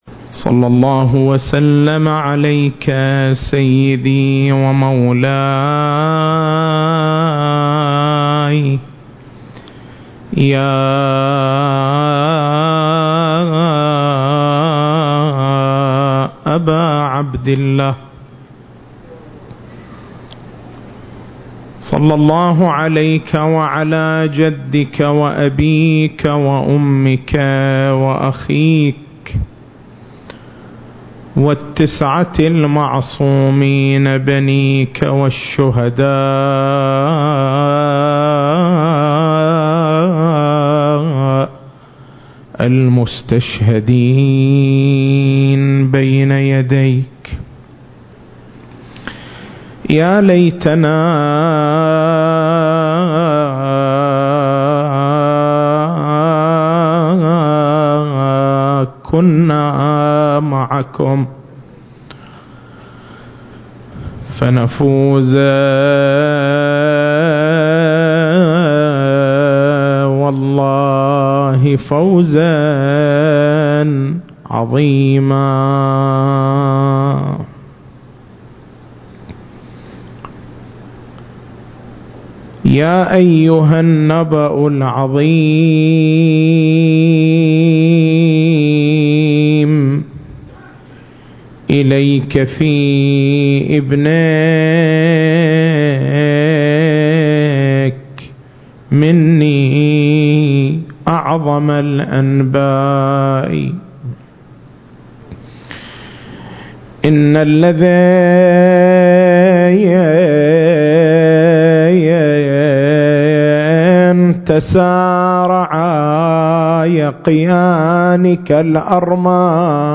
المكان: حسينية الحاج حبيب العمران التاريخ: 1440 للهجرة